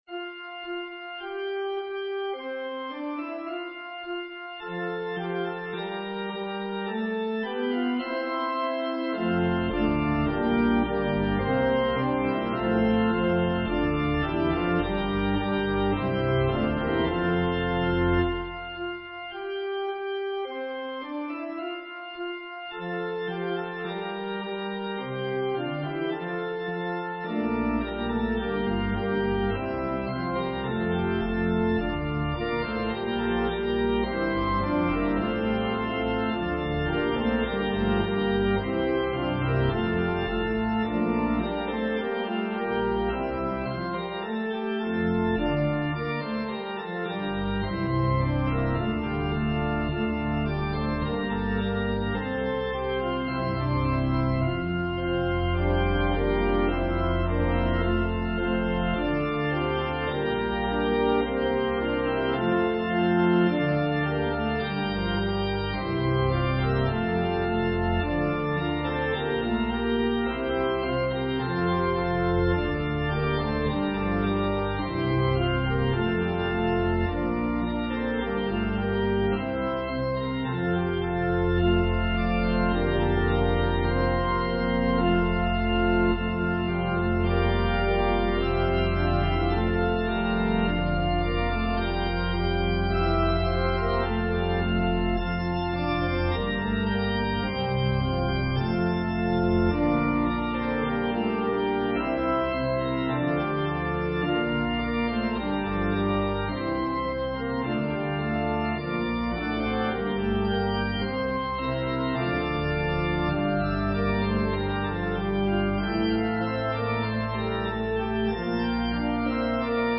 An organ solo arrangement of this seldom-sung hymn #320.
Voicing/Instrumentation: Organ/Organ Accompaniment We also have other 2 arrangements of " The Priesthood of Our Lord ".